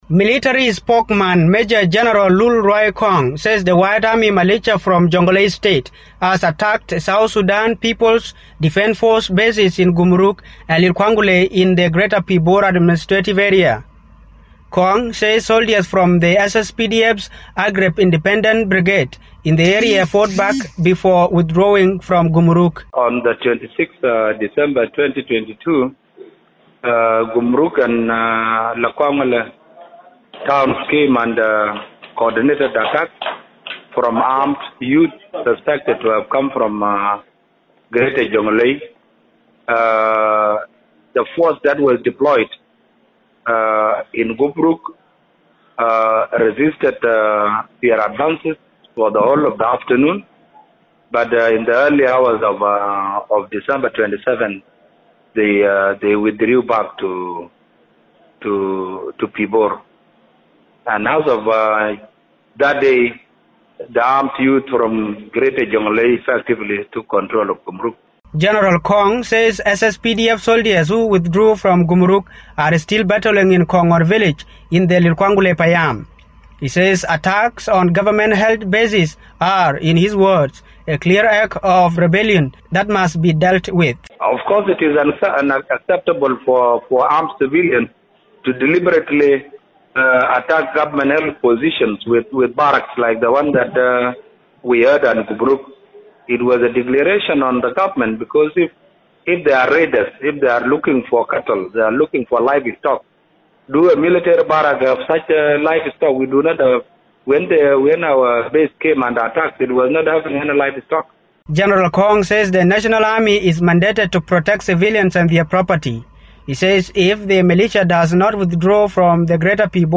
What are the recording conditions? reports from Bor.